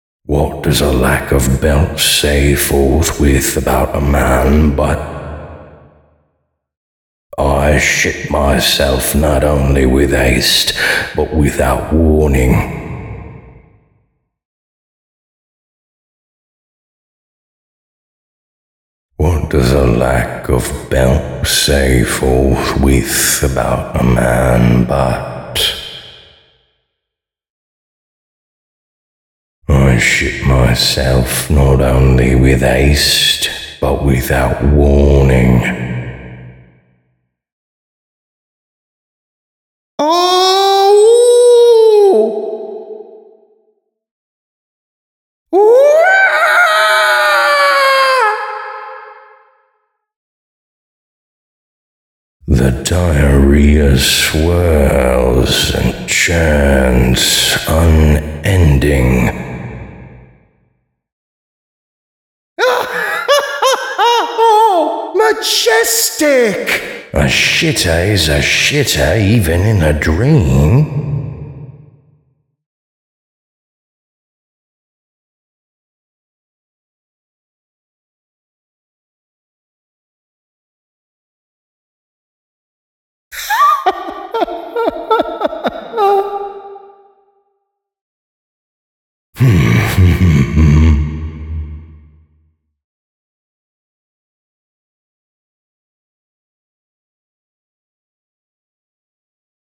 The voice is honestly quite a ways in terms of accuracy, but eh, I think it "sounds good" enough for a shitpost, like if you weren't comparing it to the actual VO, it "sounds good".